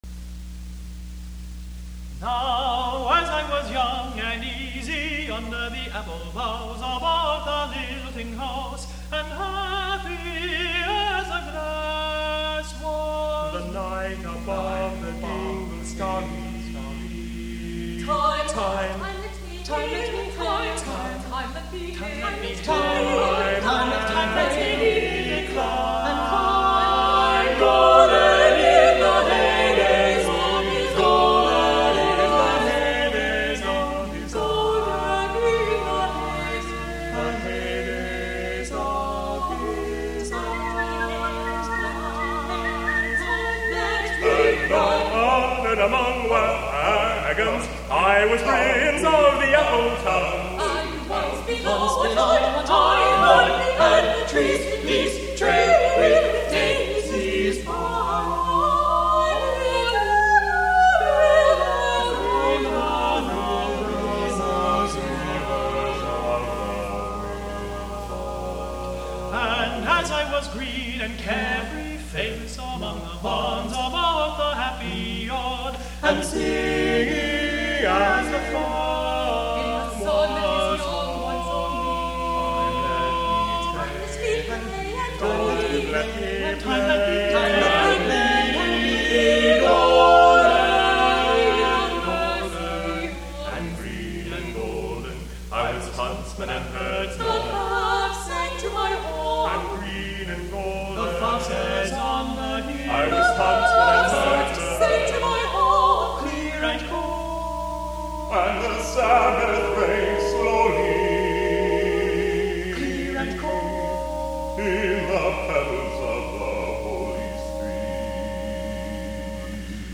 An extravagent a cappella rendition
SATTBB a cappella
the composer’s vocal sextet in Cincinnati